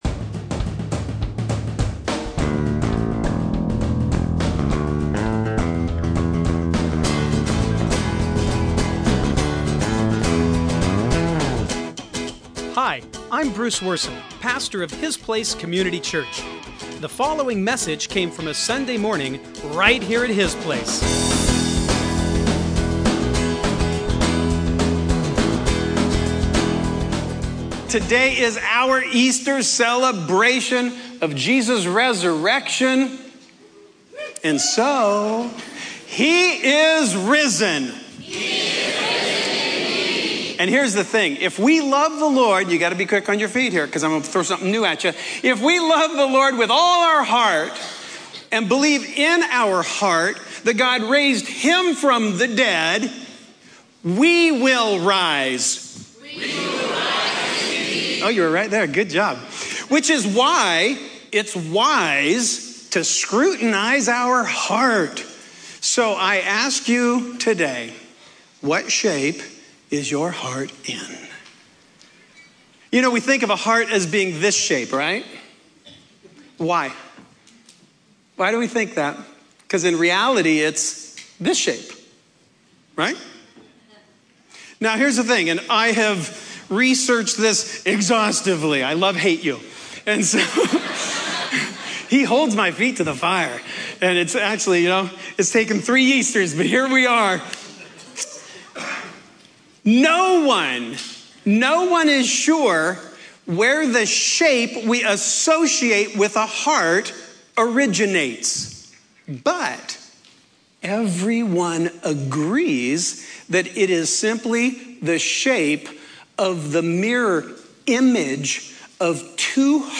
Easter Sermon